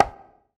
YBONGO SLA1J.wav